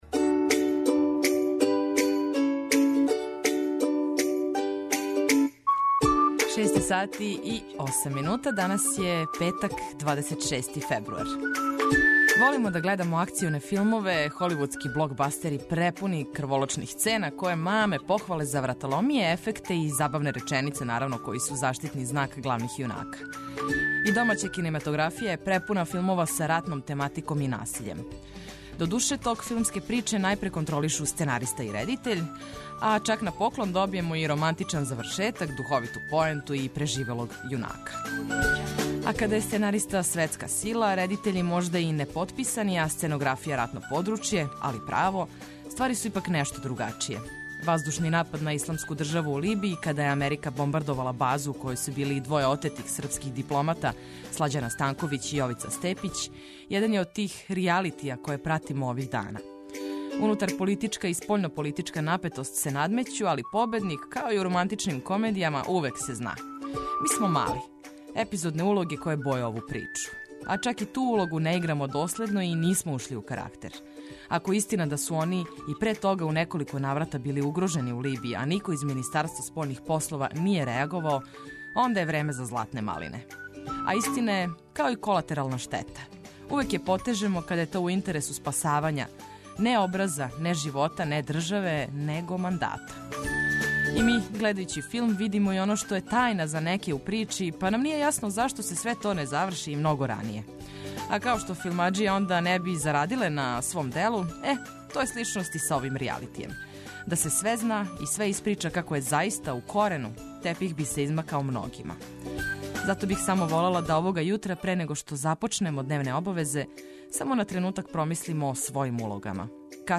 Водитељ: